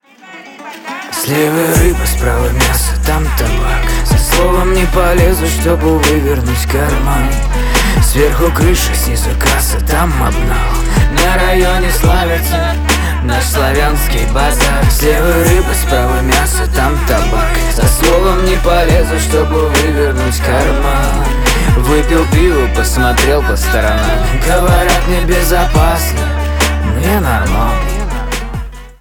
RnB
Романтические